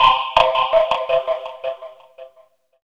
06 Vibradofoam 165 A.wav